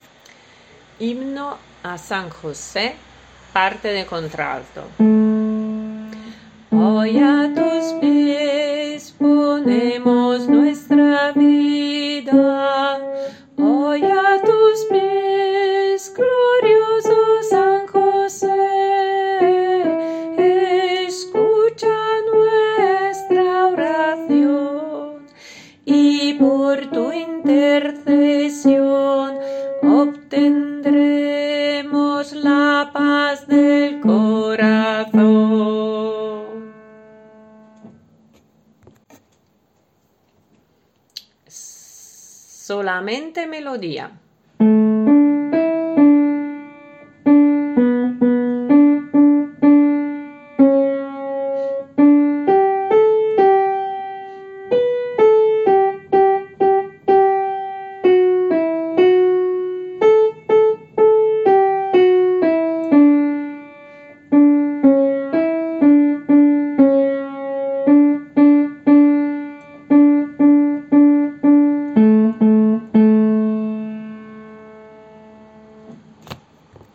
CONTRALTO
h-sanjose-contralto.mp3